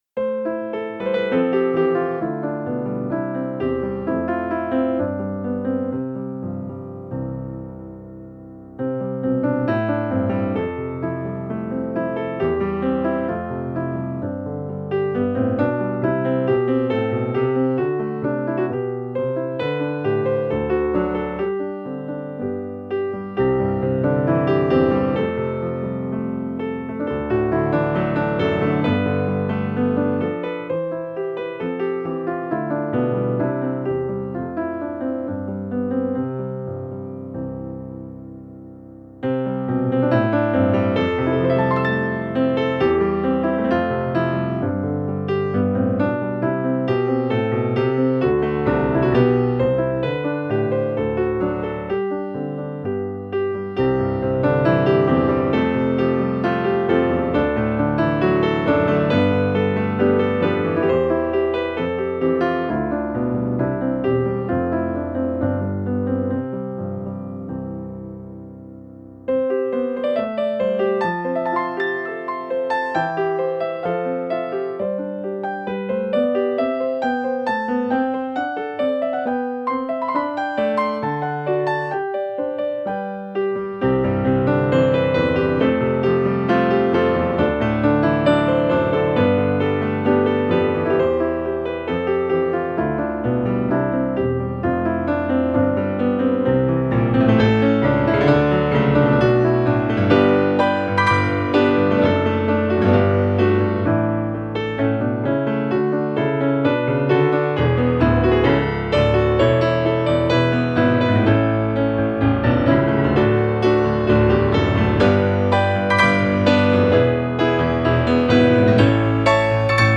Instrumental version: